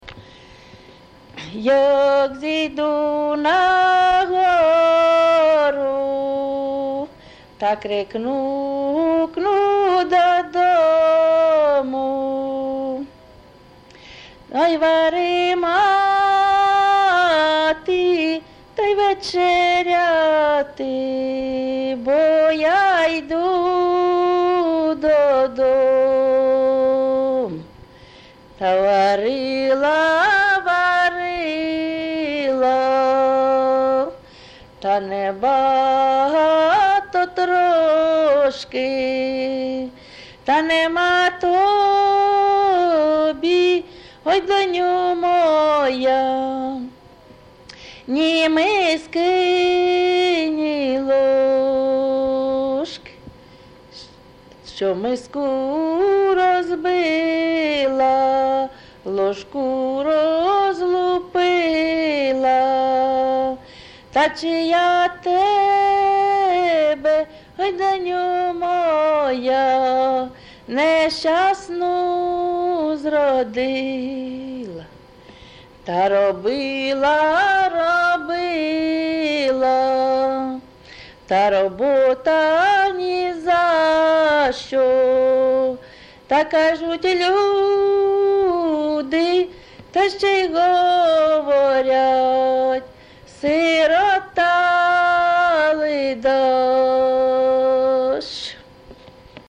ЖанрСтрокові
Місце записус. Яблунівка, Костянтинівський (Краматорський) район, Донецька обл., Україна, Слобожанщина